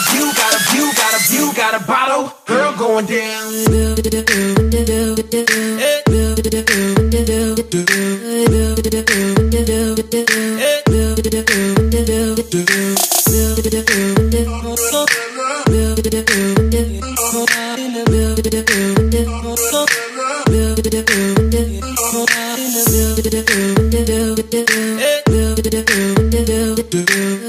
inkyz-bottle_14147.mp3